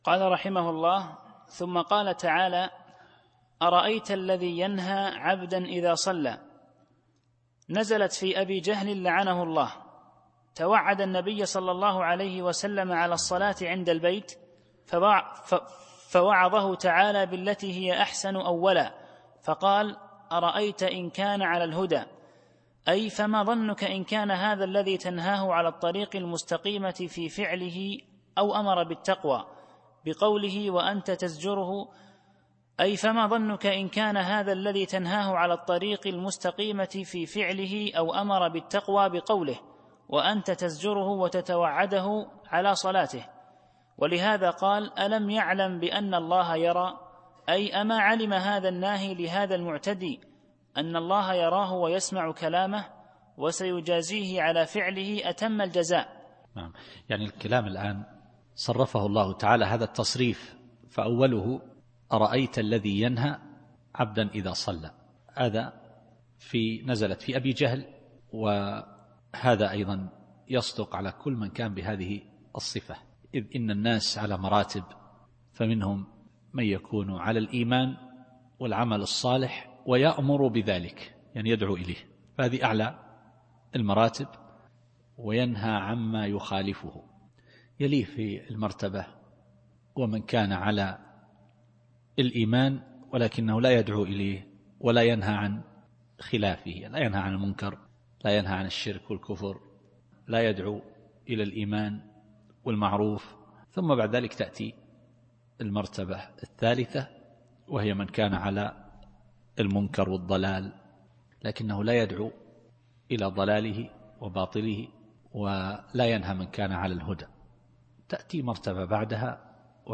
التفسير الصوتي [العلق / 14]